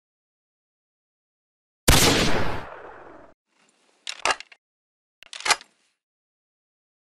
دانلود صدای اسنایپ یا اسنایپر از ساعد نیوز با لینک مستقیم و کیفیت بالا
جلوه های صوتی
برچسب: دانلود آهنگ های افکت صوتی اشیاء دانلود آلبوم صدای تک تیرانداز از افکت صوتی اشیاء